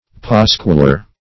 Pasquiler \Pas"quil*er\, n.